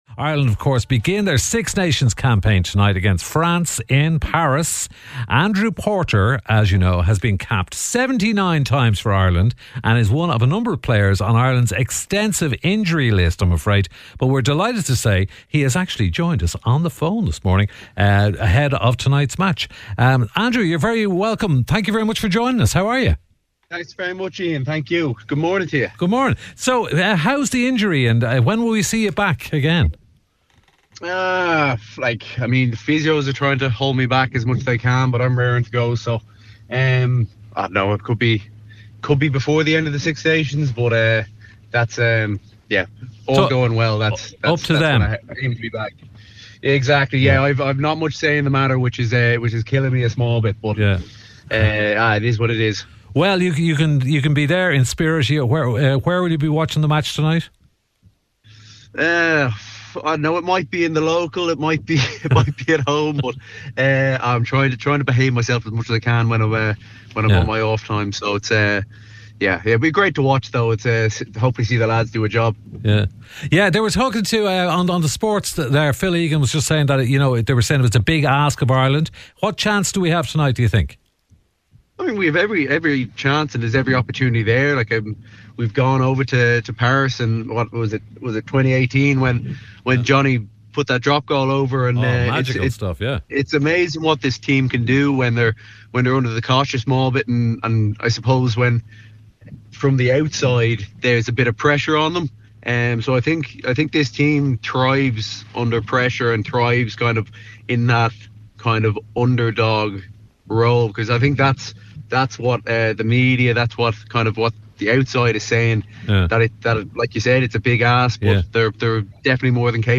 Andrew joined Ian this morning on the phone to chat injuries, comeback hopes, and what it’s like watching the lads when you’re stuck on the sideline.
Then Gift Grub crashes the call, with Donal Lenihan giving a “rugby insight” only he could deliver, and Johnny Sexton laying into Ian for asking the important questions — like birthdays.